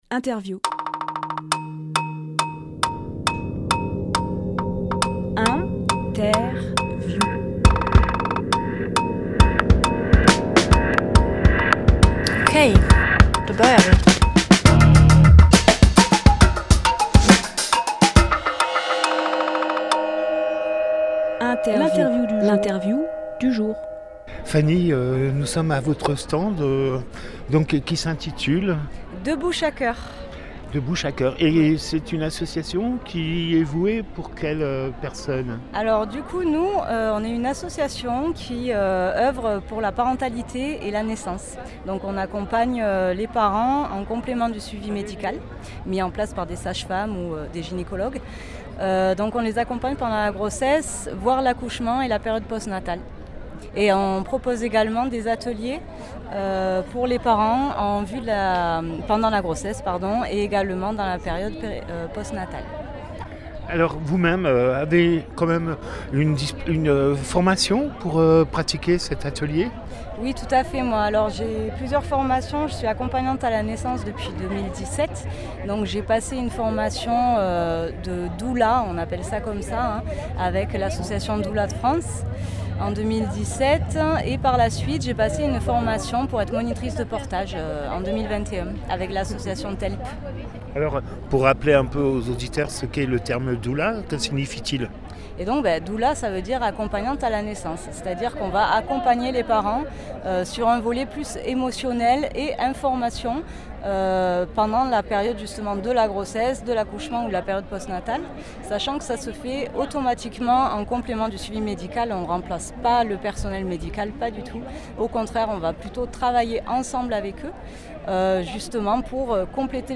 Emission - Interview De Bouche à Cœur Publié le 30 septembre 2024 Partager sur…
Lieu : Forum des assos Die